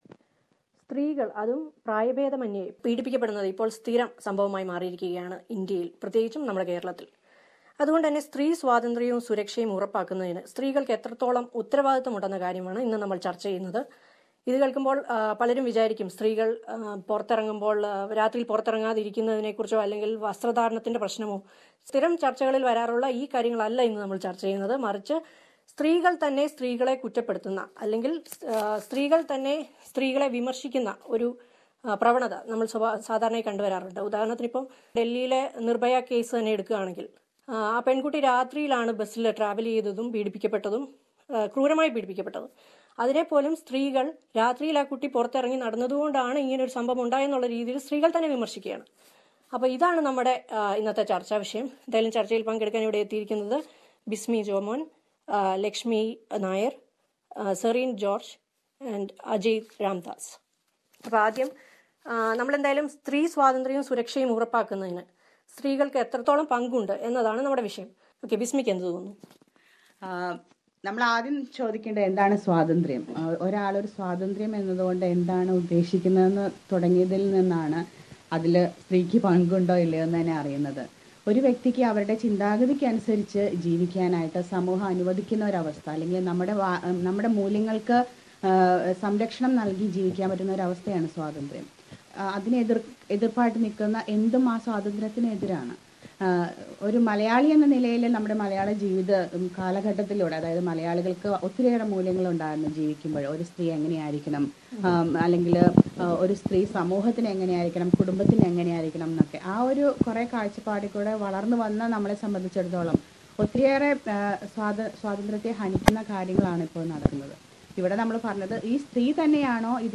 Ahead of Internatioanl Women's Day, SBS Malayalam holds a panel discussion about the responsibilities of women to ensure their freedom and pride.